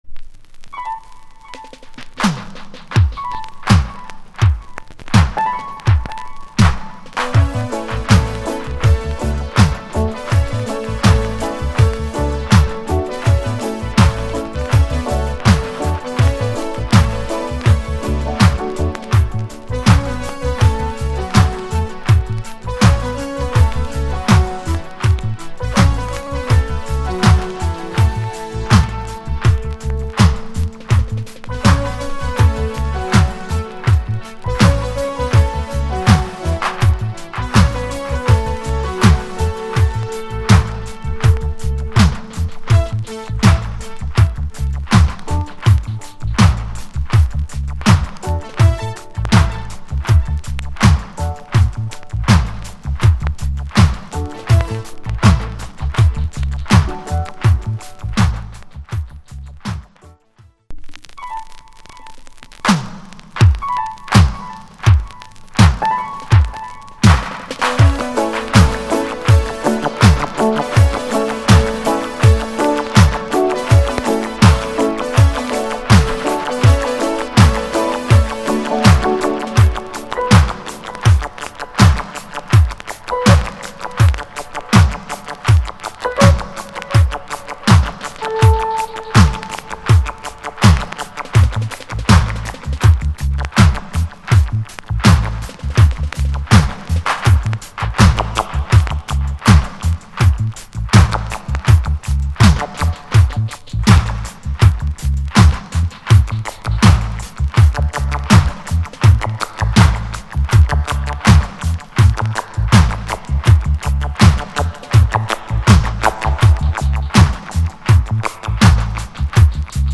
リディムのシンセ・インスト。** 細かな傷が多く所々チリパチノイズ入ります。